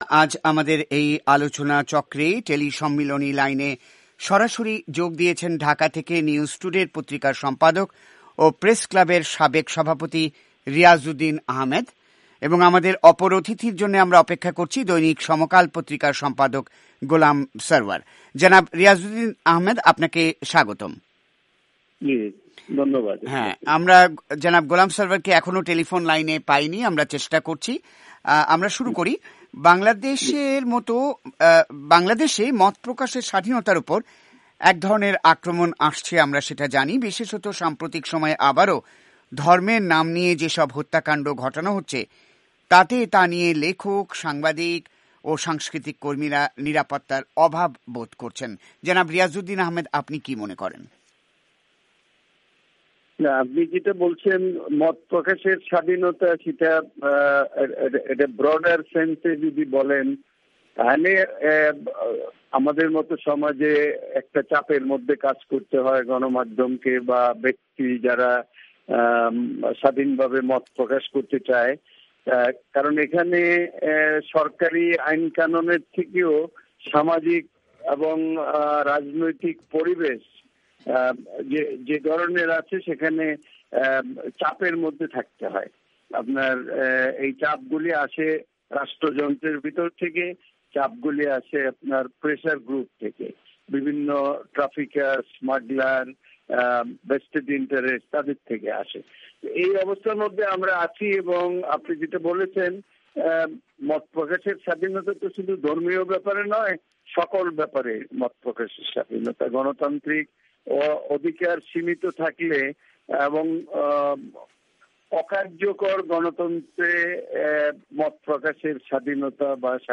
বাংলাদেশে বাক স্বাধীনতা ও জঙ্গিবাদ প্রসঙ্গ : একটি আলোচনাচক্র
এসব জিজ্ঞাসা নিয়েই একটি আলোচনা চক্র।